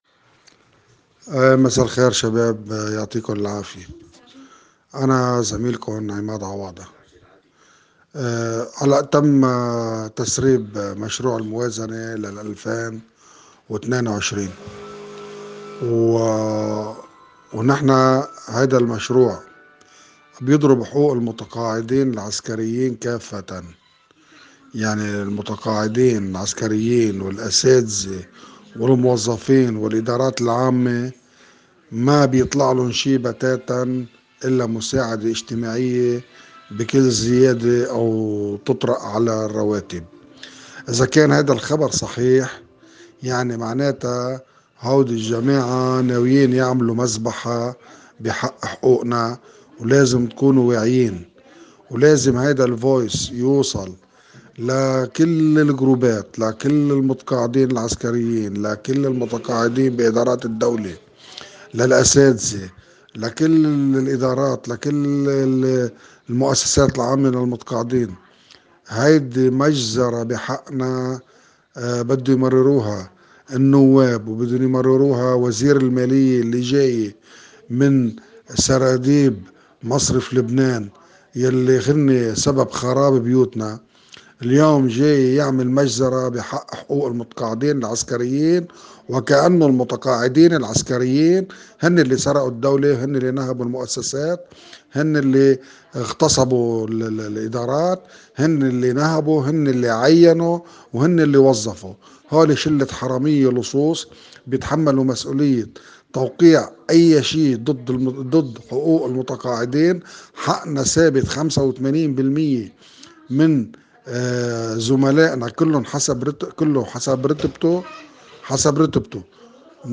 كما وزّع عدد من العسكريين المتقاعدين رسائل صوتية تدعو للتحرّك دفاعاً عن حقوقهم.